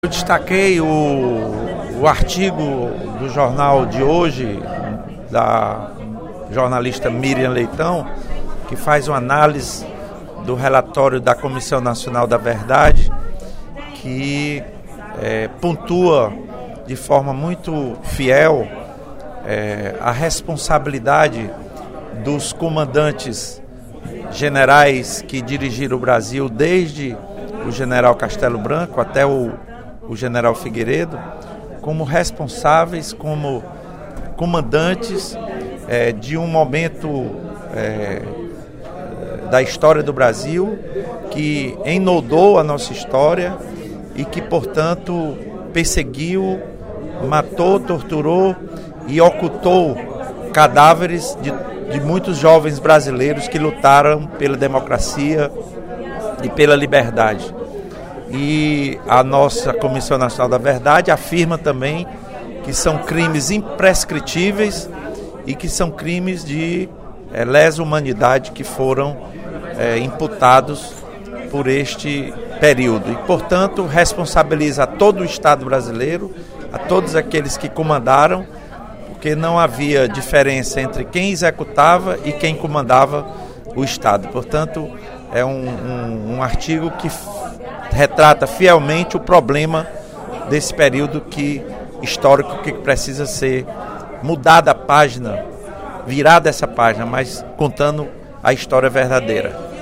No primeiro expediente da sessão plenária desta quinta-feira (11/12), o deputado Lula Morais (PCdoB) parabenizou o trabalho da Comissão Nacional da Verdade (CNV) na elucidação das violações praticadas contra militantes políticos durante a ditadura militar.